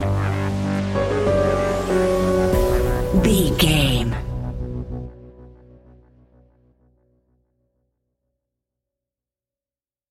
Scary Tense Piano Horror Film Music Stinger.
Aeolian/Minor
ominous
eerie
drums
synthesiser
horror music